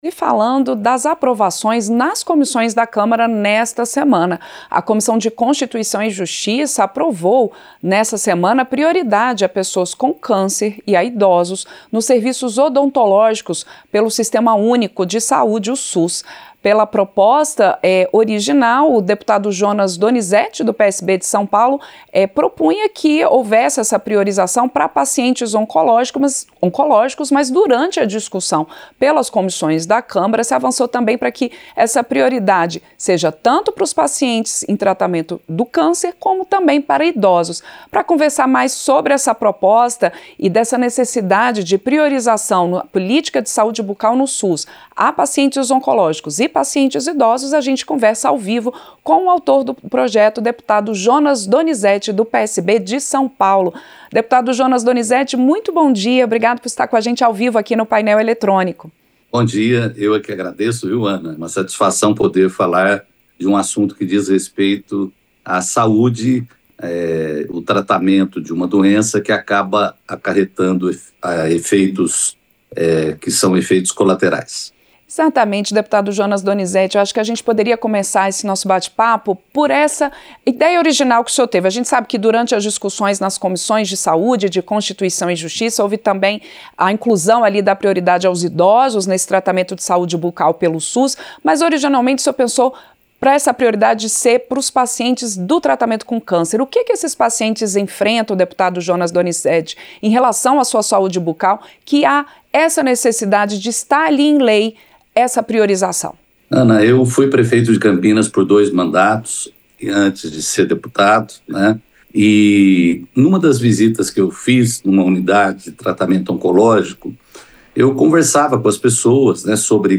Entrevista - Dep. Jonas Donizette (PSB-SP)